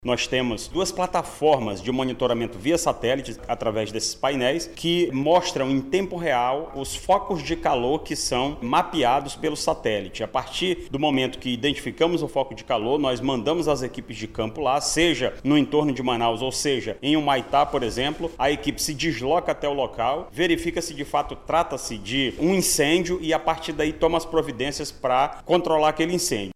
O comandante-geral do Corpo de Bombeiros, o coronel Orleilso Muniz, explica como ocorre o processo de monitoramento.